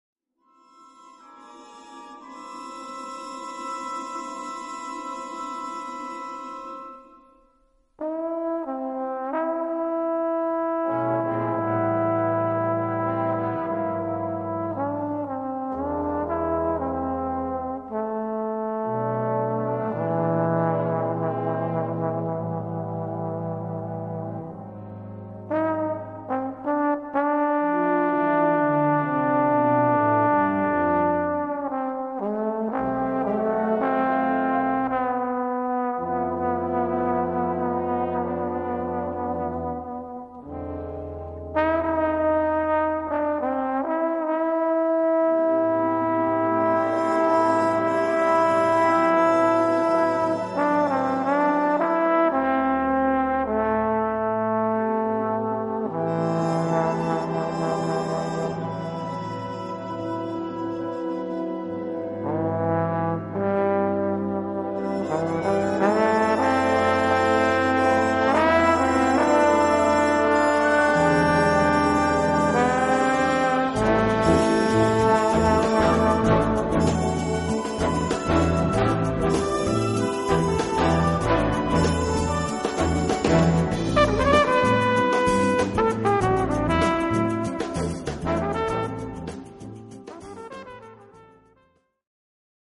Gattung: Film & Musical
Besetzung: Blasorchester